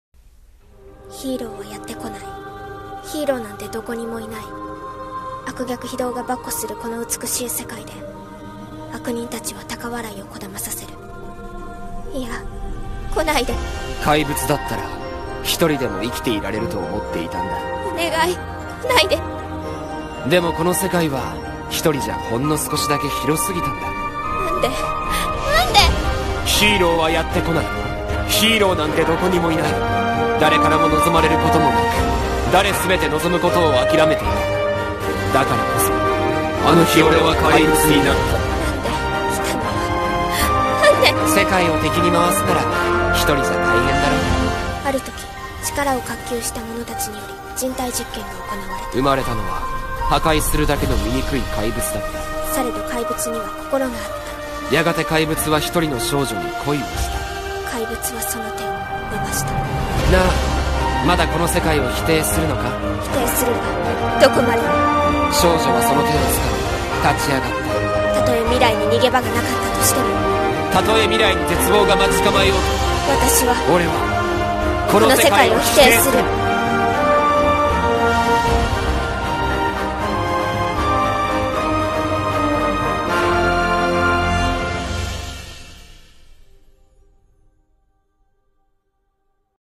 声劇「怪物たちは否定する」